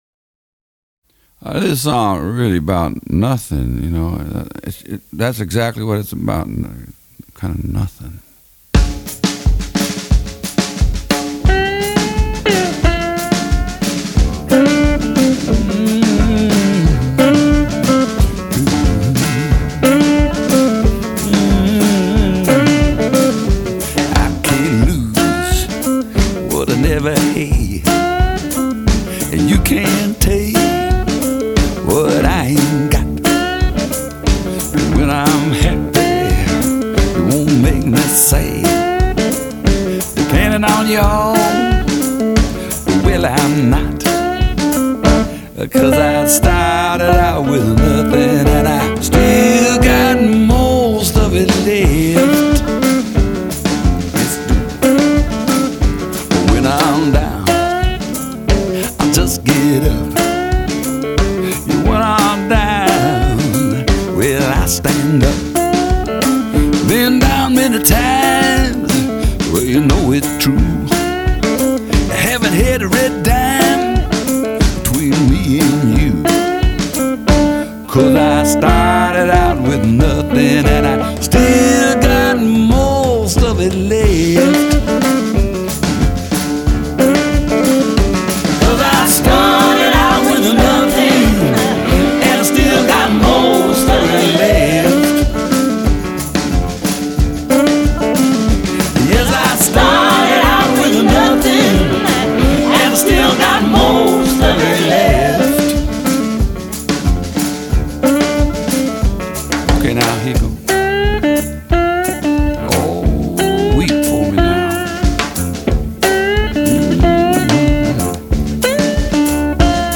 blues
guitar
The mp3 as it has a better sound quality,